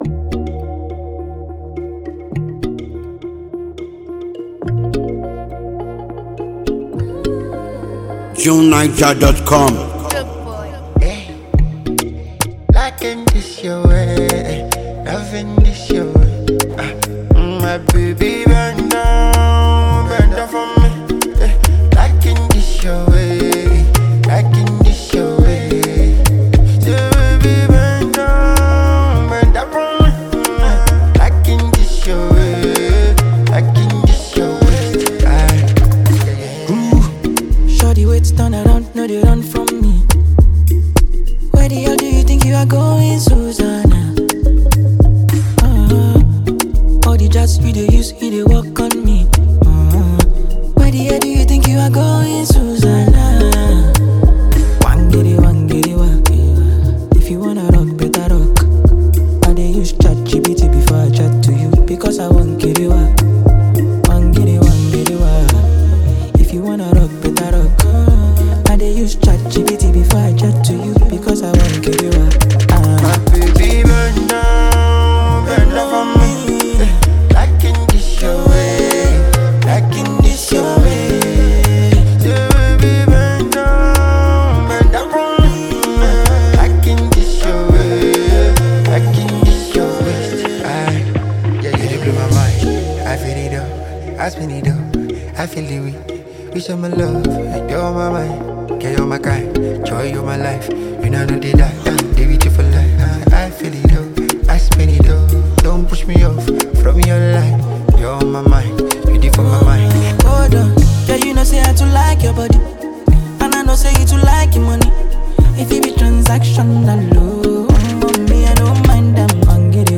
lively and buzzing new song